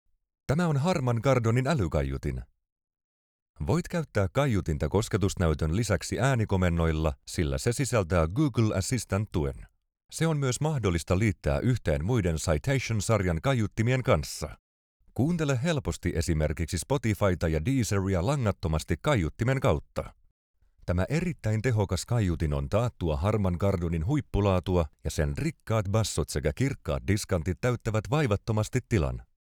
Male
30s, 40s, 50s
Authoritative, Confident, Corporate, Deep, Friendly, Warm, Versatile, Energetic, Approachable, Engaging, Soft
General Finnish, and also Southwestern Finnish. Also English with a slight accent.
I have a deep, expressive, persuasive and memorable voice.
Microphone: Sennheiser MKH416